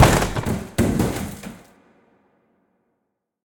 tableSlam.ogg